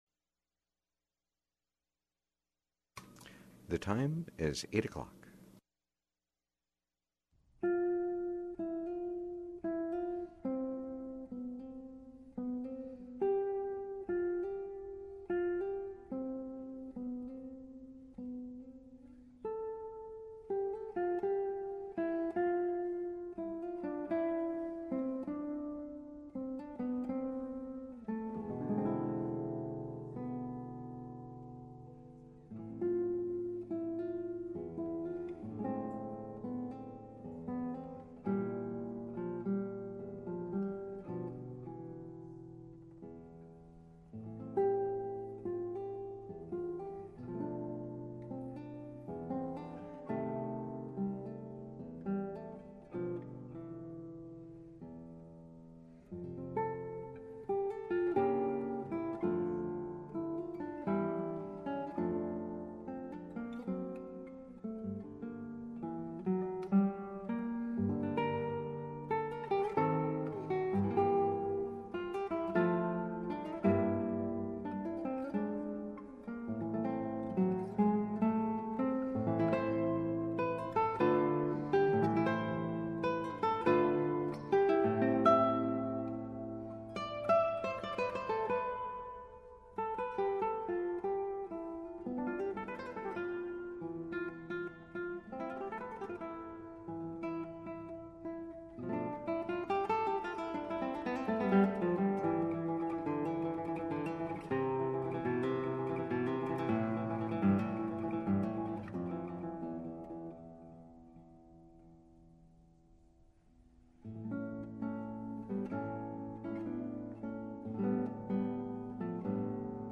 Radio Lillooet Interview